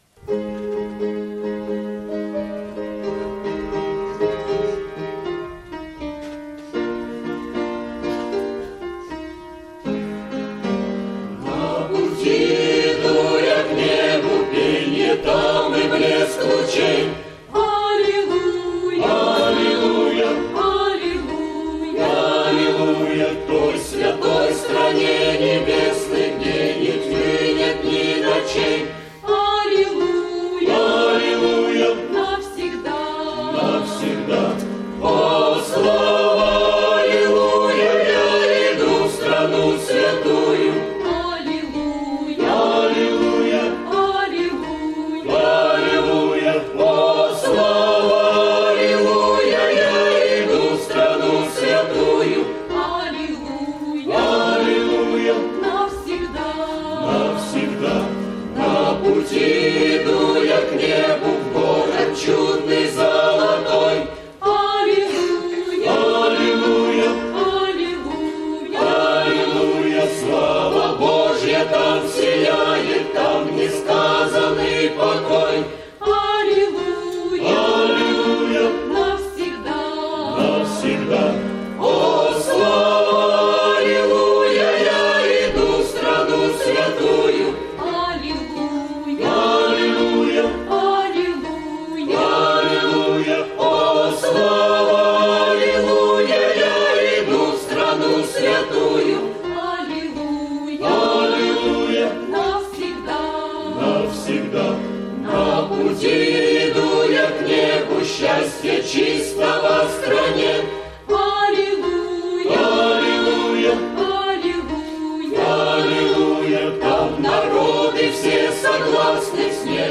Богослужение 12.09.2010 mp3 видео фото
На пути иду я к небу - Хор (Пение)